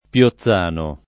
[ p L o ZZ# no ]